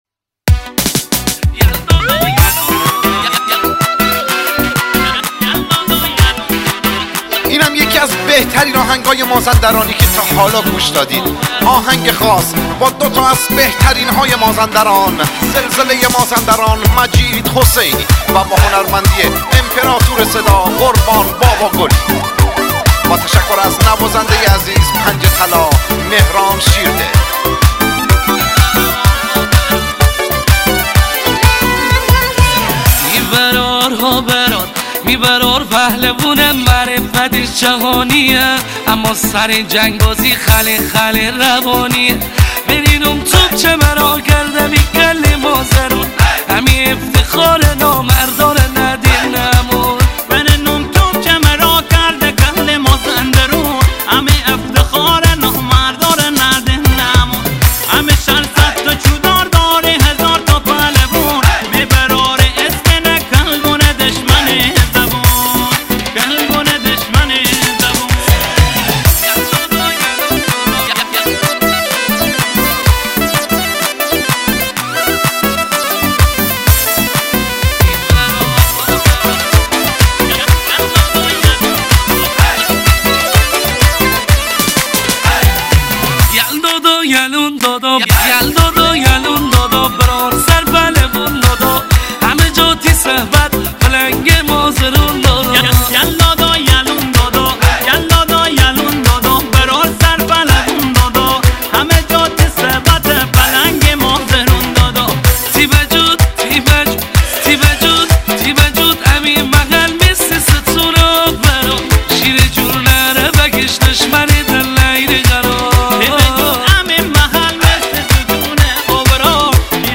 مازندرانی شوتی لاتی بیس دار